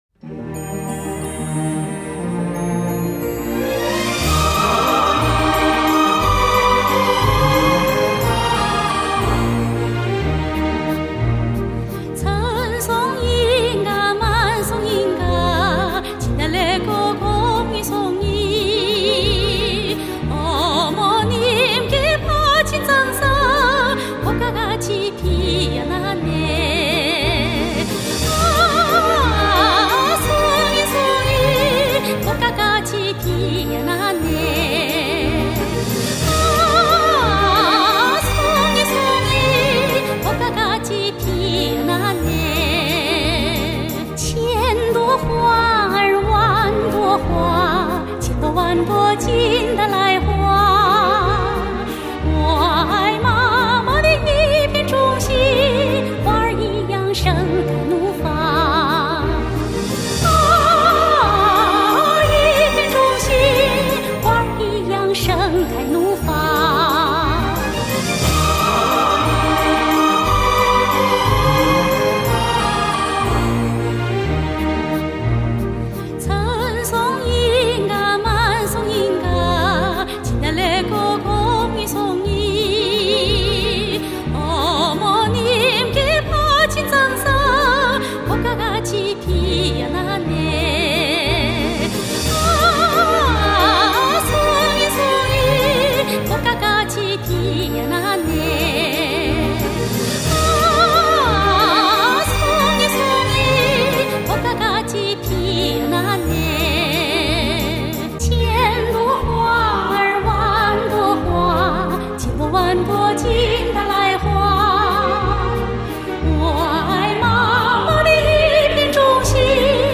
朝鲜歌剧
朝鲜族，歌剧表演艺术家、国家一级演员。
令人难忘的怀旧金曲，韵味十足的真情演绎。